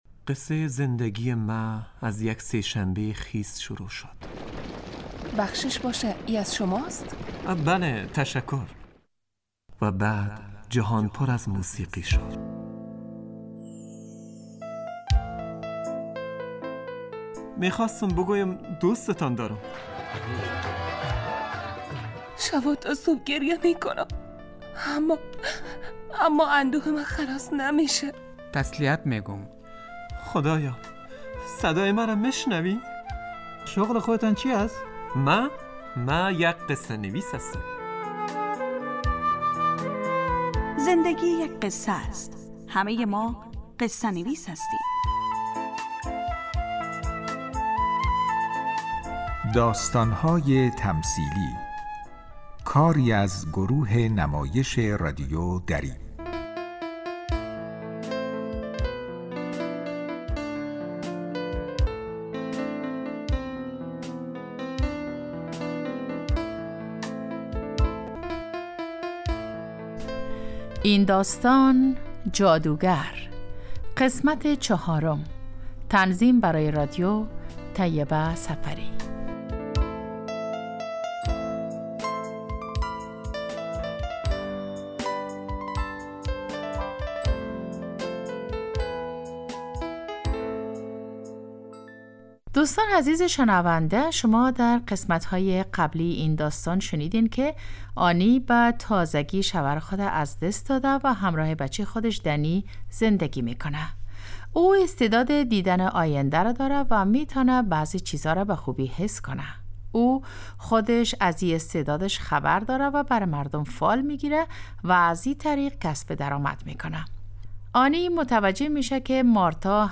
داستانهای تمثیلی نمایش 15 دقیقه ای هستند که روزهای دوشنبه تا پنج شنبه ساعت 03:25عصربه وقت افغانستان پخش می شود.